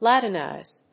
音節Lat・in・ize /lˈæṭənὰɪz/ [時に l[N16-A12A]]